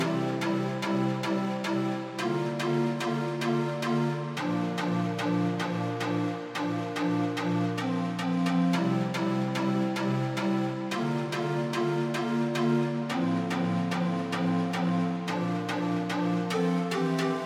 热带包4盘长笛和弦
Tag: 110 bpm House Loops Woodwind Loops 2.94 MB wav Key : Unknown